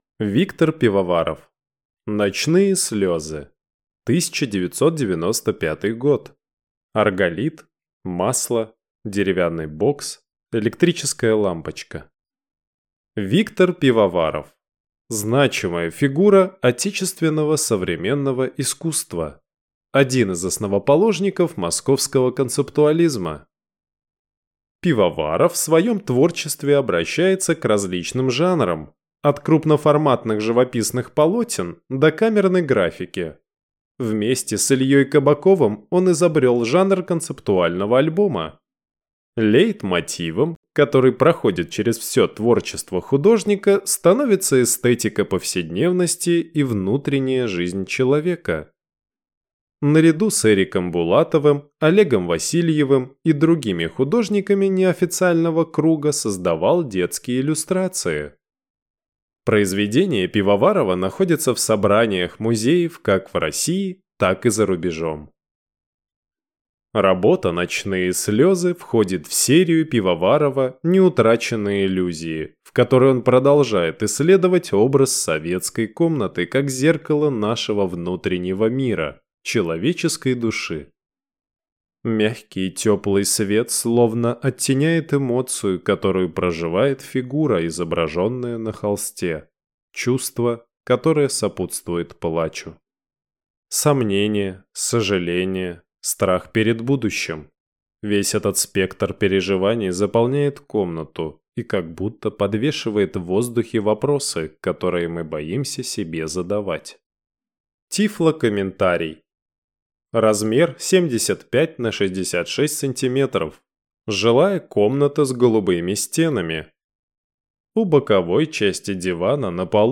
Тифлокомментарий к картине Виктора Пивоварова "Ночные слезы"